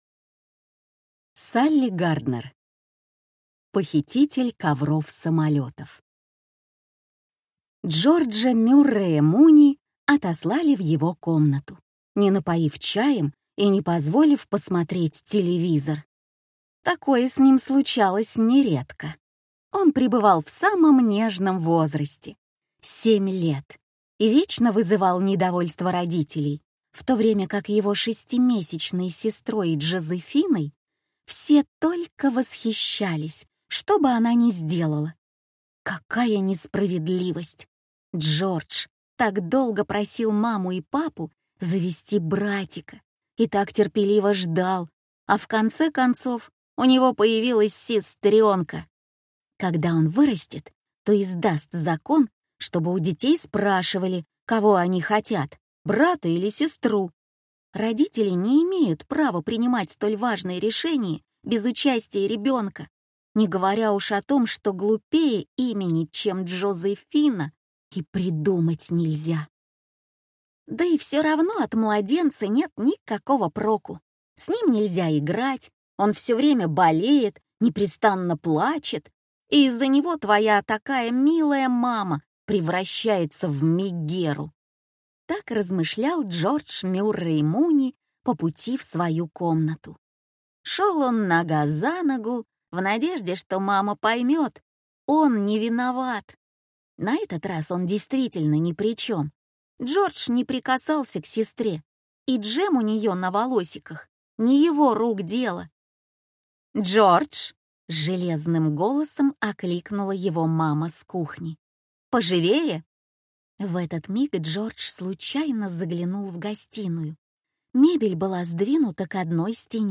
Аудиокнига Похититель ковров-самолётов | Библиотека аудиокниг